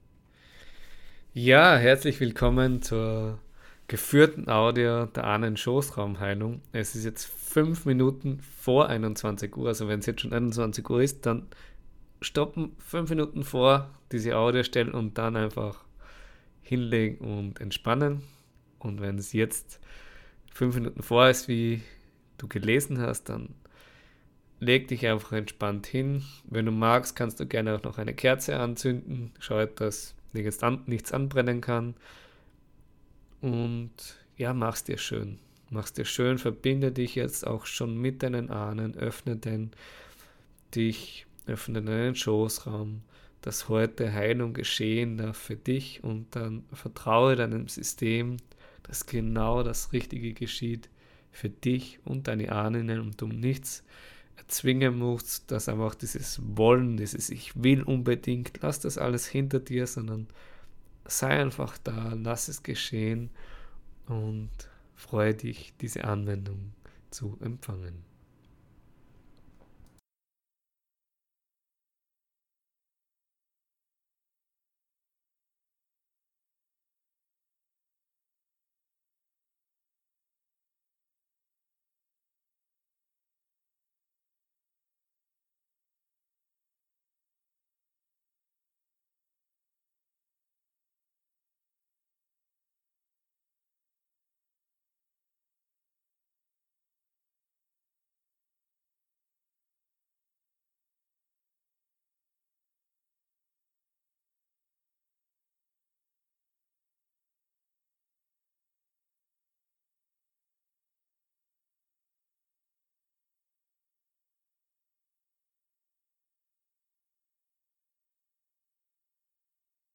Gefuehrte-Audio-Ahnen-Schossraumheilung-ohne-Musik-neu.mp3